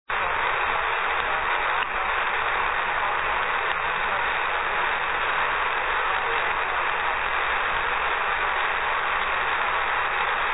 Byl použit Nf kompresor s nastavitelnou frekvenční charakteristikou a FT817.
SIGNAL BEZ KOMPRESE (21 KB)